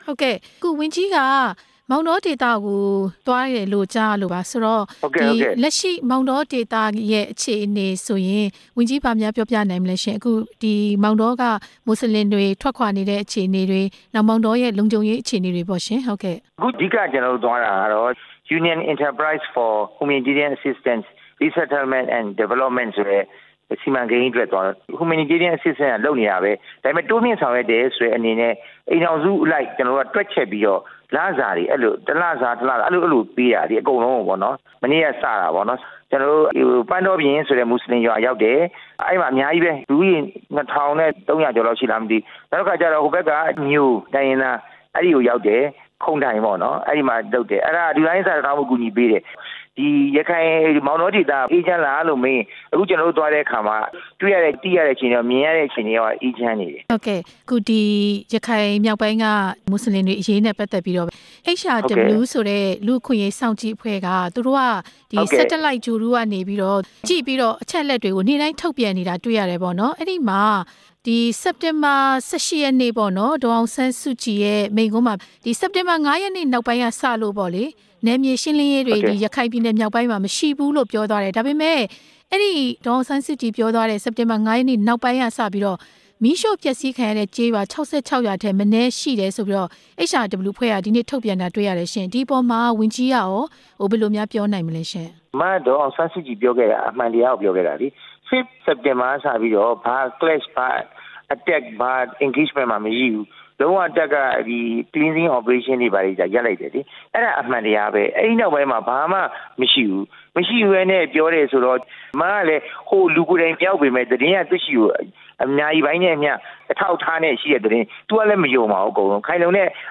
လူ့အခွင့်အရေးစွပ်စွဲချက်တွေအပေါ် ဝန်ကြီးဒေါက်တာဝင်းမြတ်အေးနဲ့ မေးမြန်းချက်